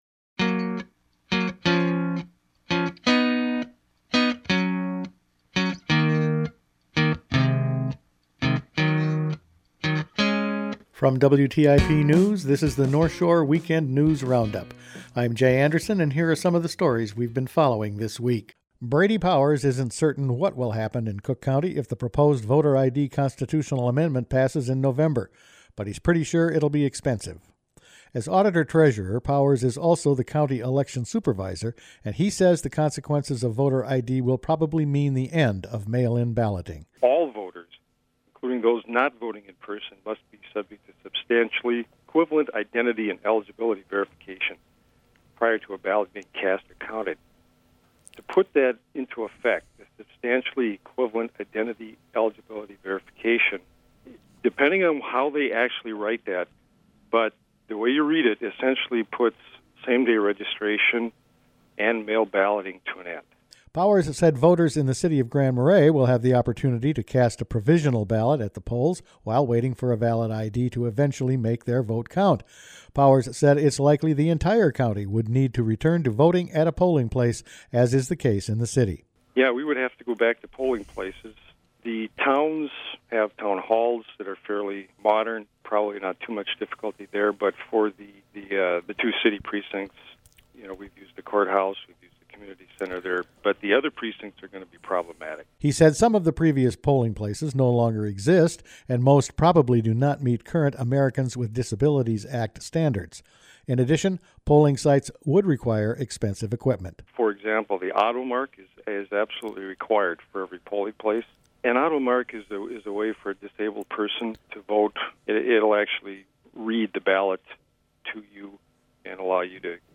Each weekend WTIP news produces a round up of the news stories they’ve been following this week. The Voter ID amendment could signal some changes locally if it passes and the city and its former pool architects have settled…all in this week’s news.